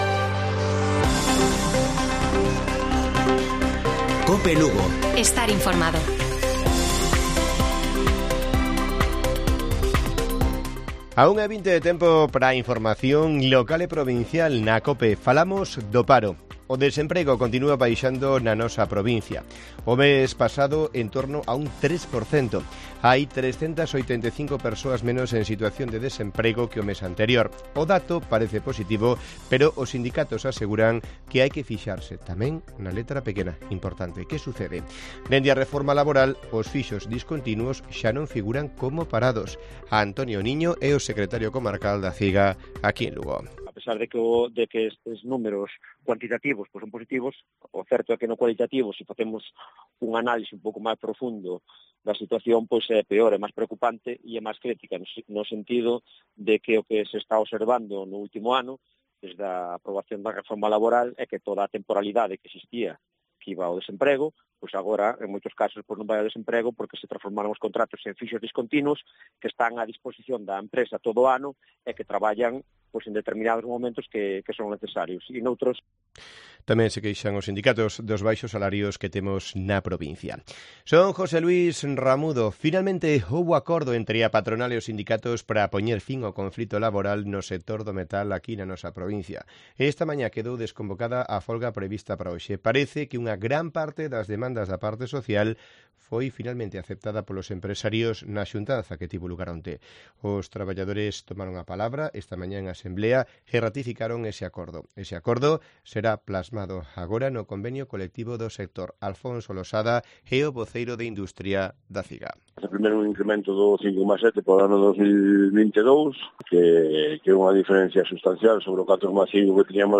Informativo Mediodía de Cope Lugo. 02 de junio. 13:20 horas